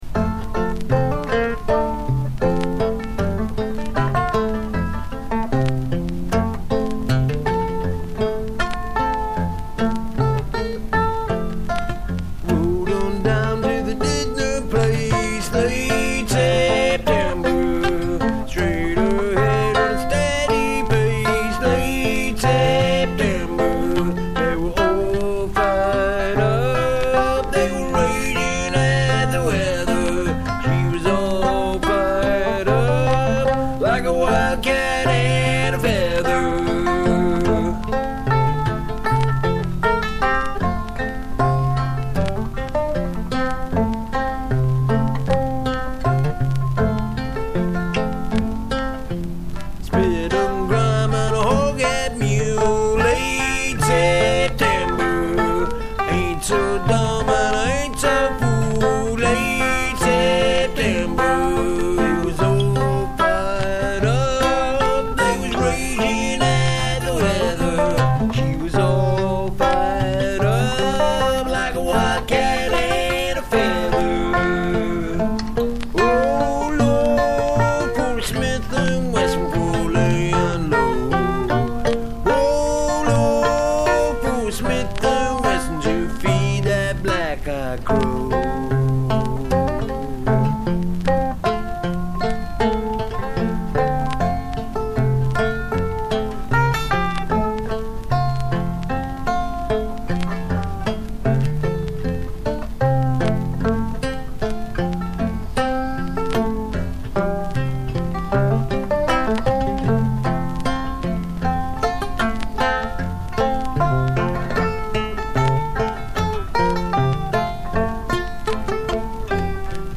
Accordion, Mandola, Mandolin